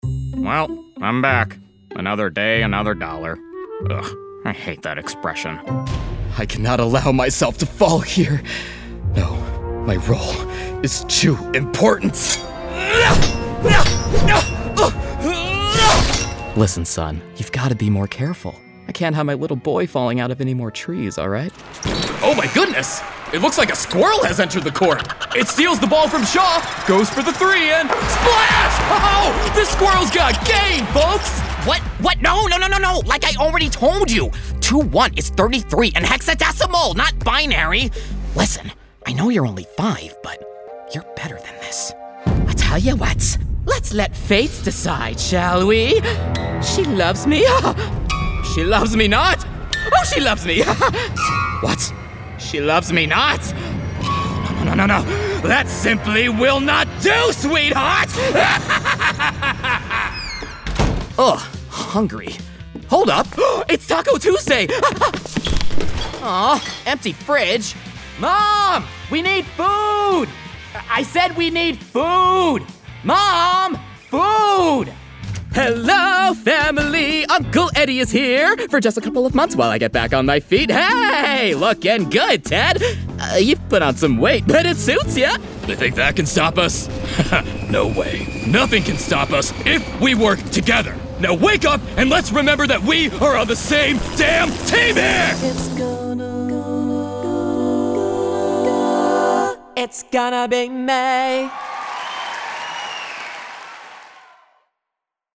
ANIMATION 🎬
comedy
teenager
broadcast level home studio